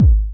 SWKICK05.wav